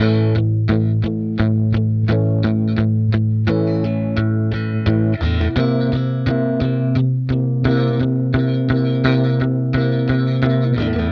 Track 02 - Guitar Rhythm 03.wav